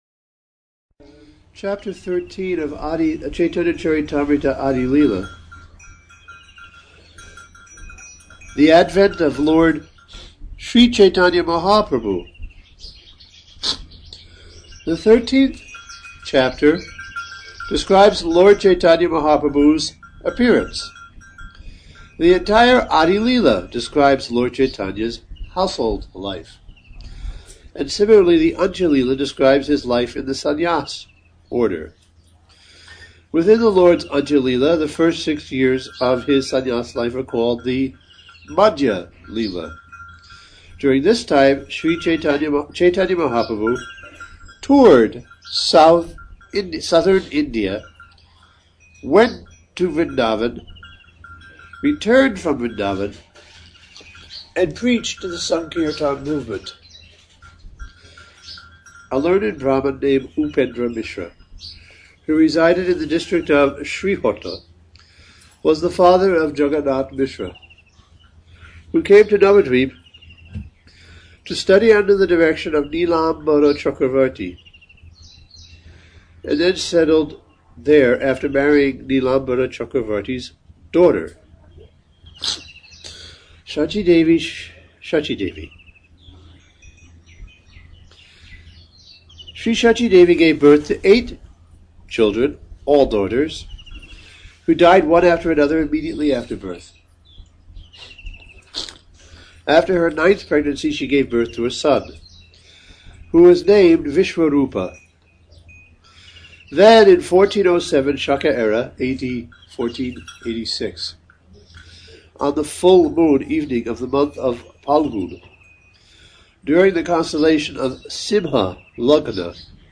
Festival Audio Lecture